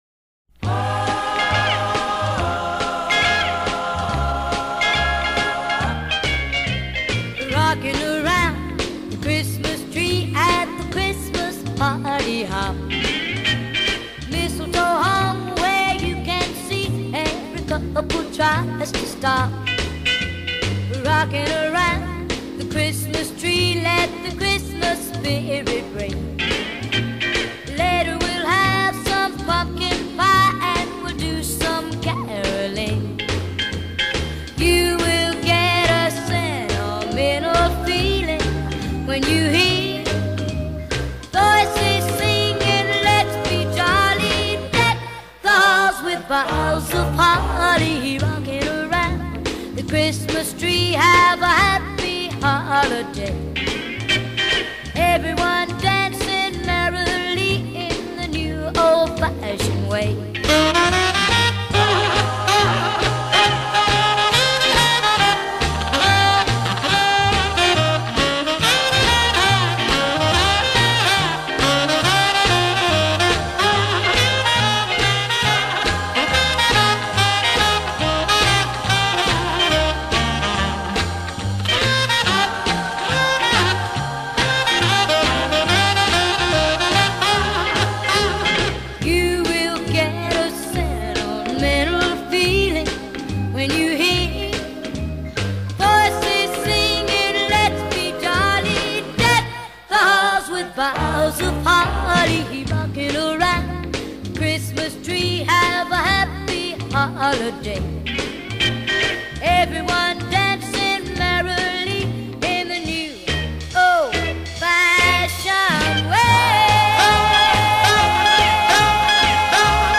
A Little Festive Music